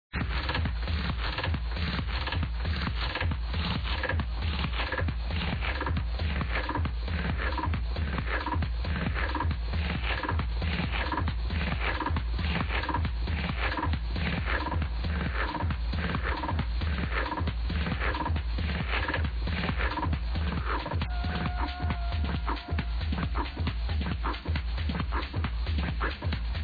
House track